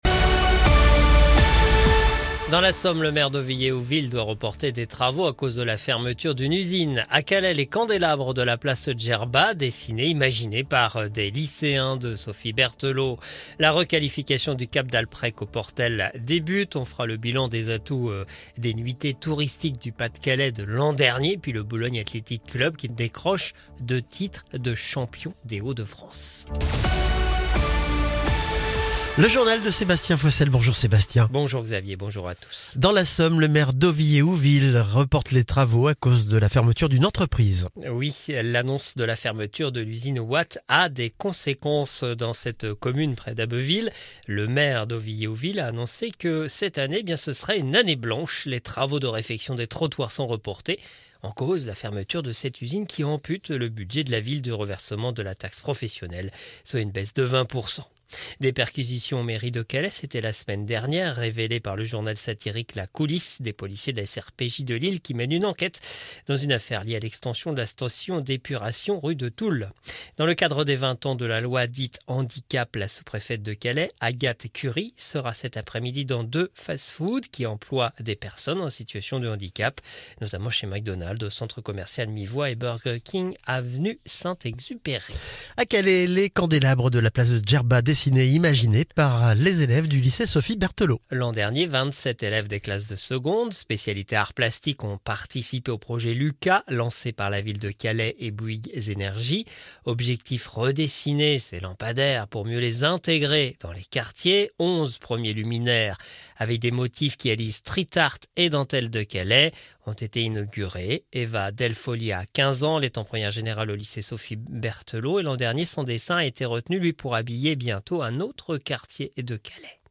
Le journal du mardi 11 février 2025